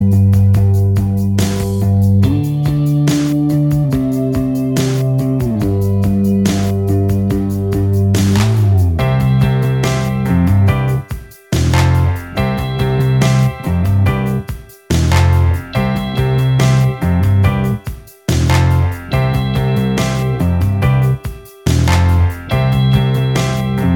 Minus Guitars Indie / Alternative 2:57 Buy £1.50